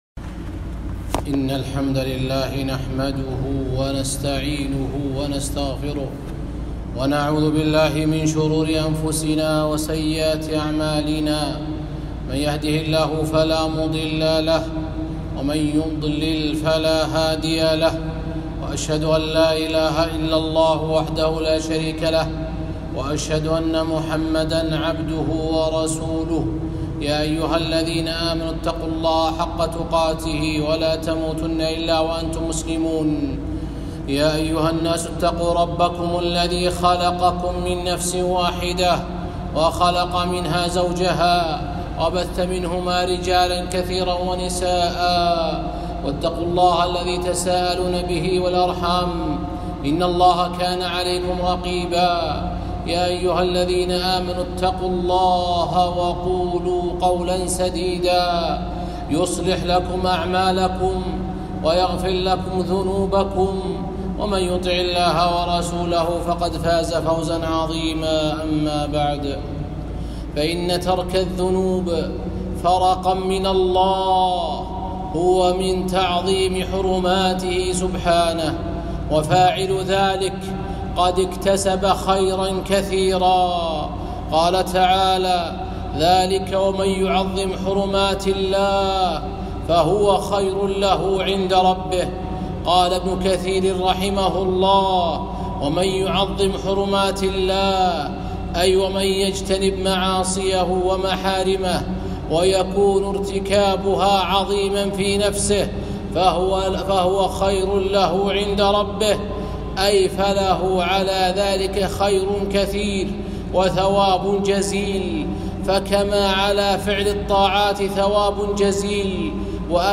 خطبة - تعظيم حرمات الله